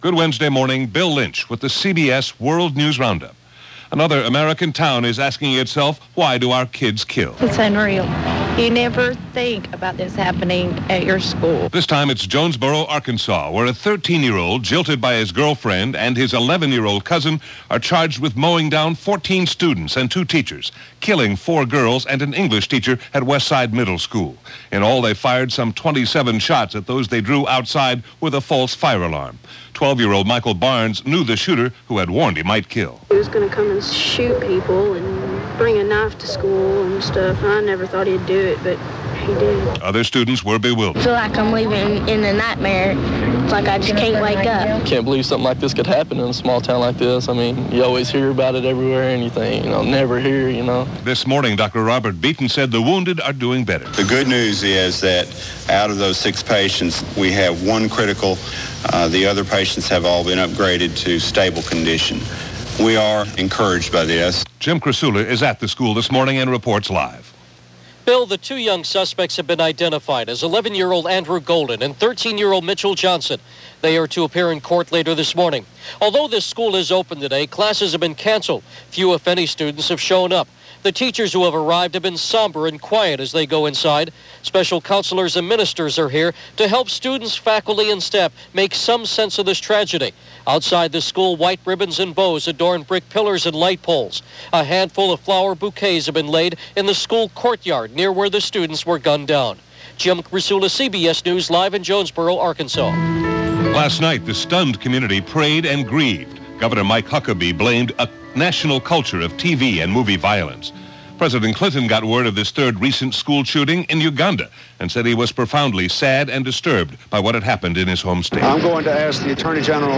And that’s just a small slice of news for this March 25, 1998 as reported by The CBS World News Roundup.